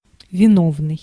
Ääntäminen
IPA : ['kʌl.prɪt]